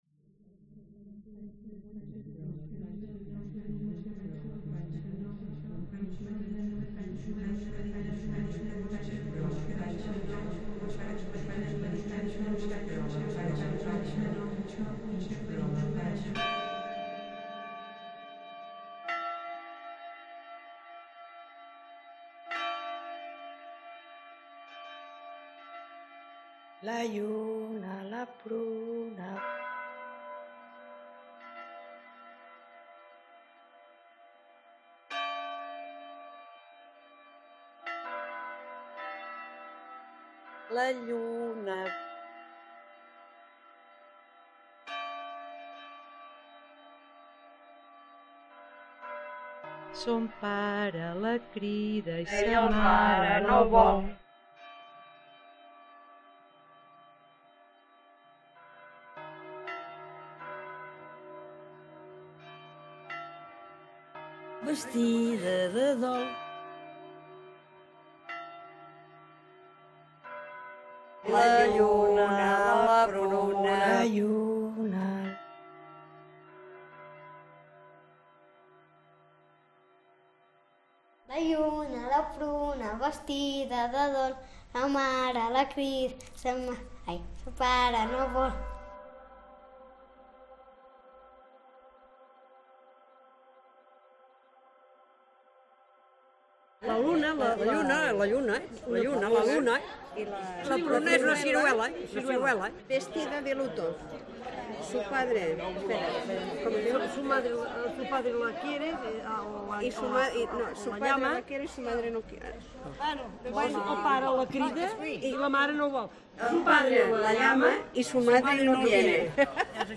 Site Specific Sound Installation
5 Speakers